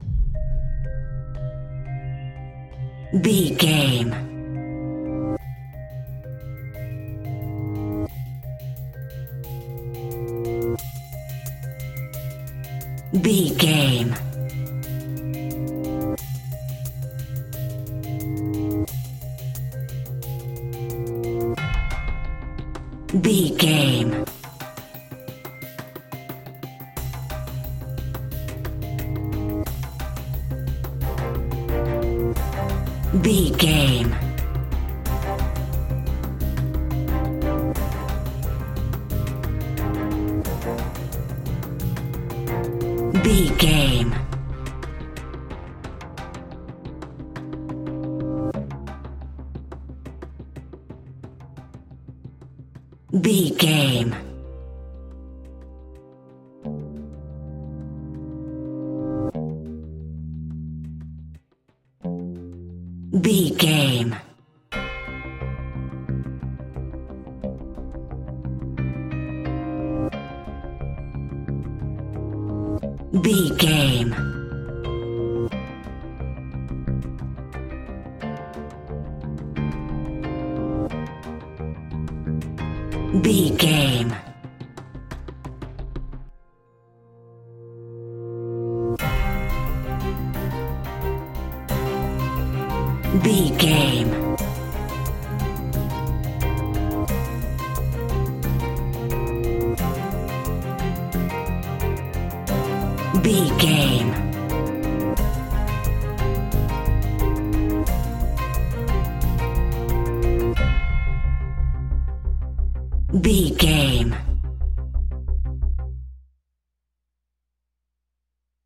Ionian/Major
C♭
electronic
techno
trance
synths
synthwave
instrumentals